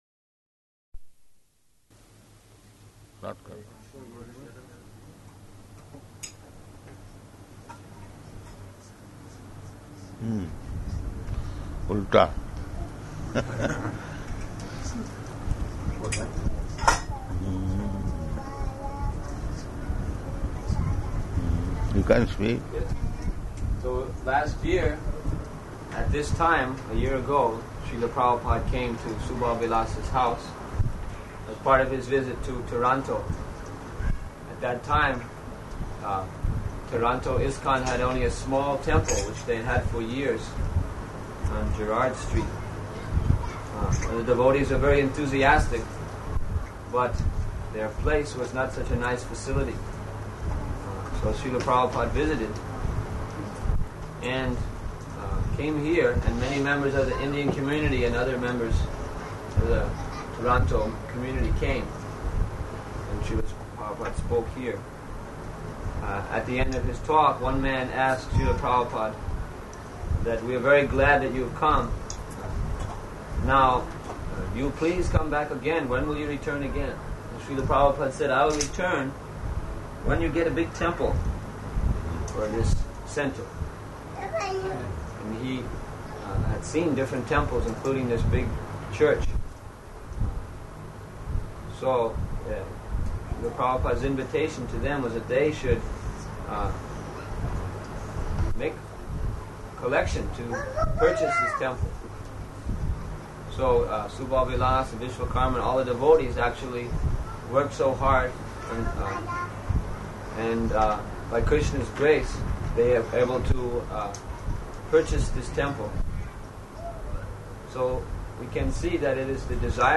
-- Type: Lectures and Addresses Dated: June 19th 1976 Location: Toronto Audio file